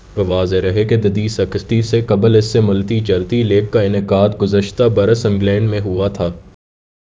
deepfake_detection_dataset_urdu / Spoofed_TTS /Speaker_11 /12.wav